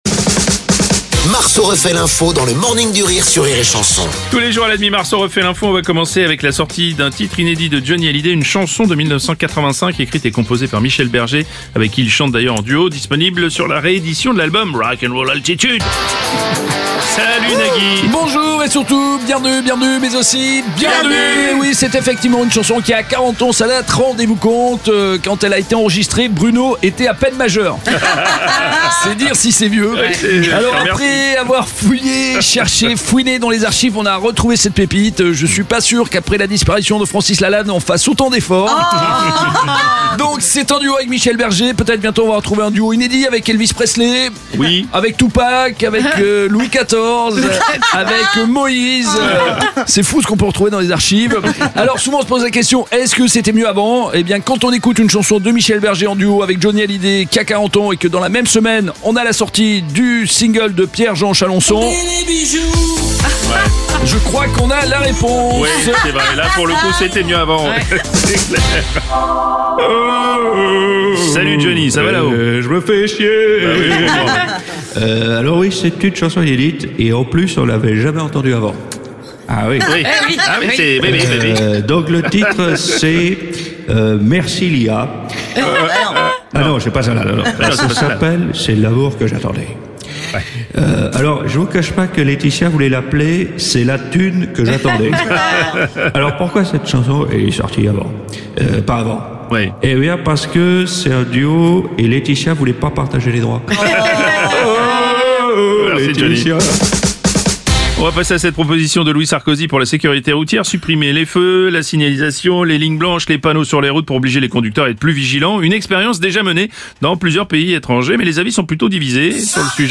L’imitateur
en direct à 7h30, 8h30, et 9h30.